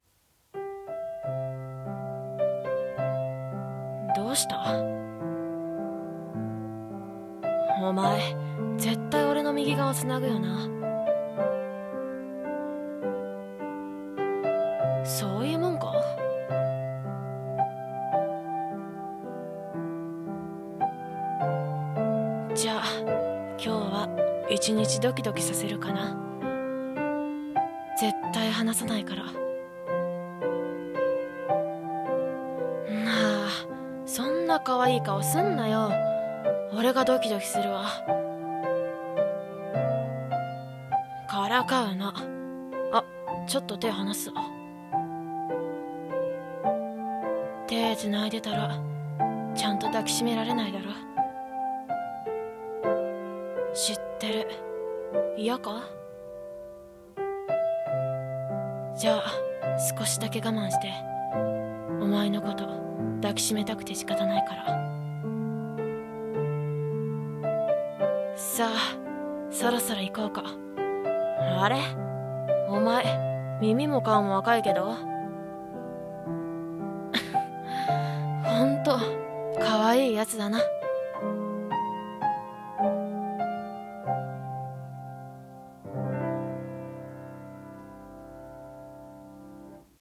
声劇【離さない手】